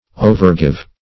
Search Result for " overgive" : The Collaborative International Dictionary of English v.0.48: Overgive \O`ver*give"\, v. t. To give over; to surrender; to yield.